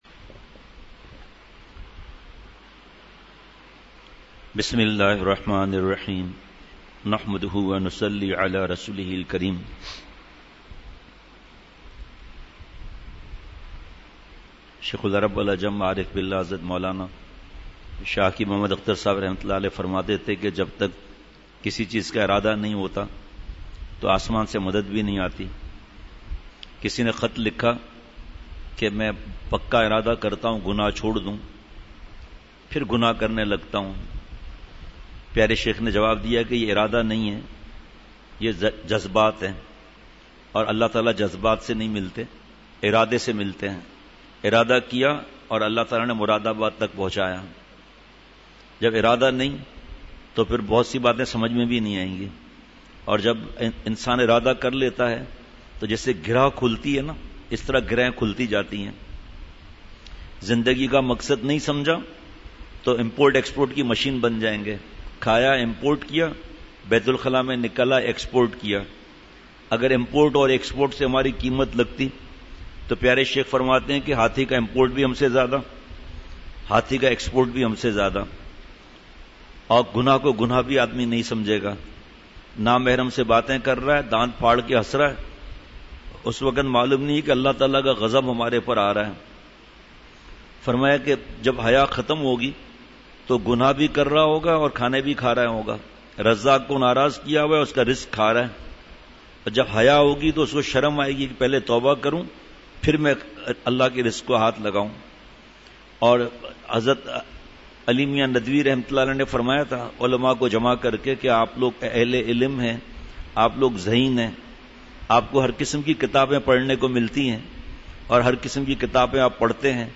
مجلس ۸ جولائی ۲۰۱۹ء بعد فجر : کسی ایک اللہ والے کو نمونہ بنائیں !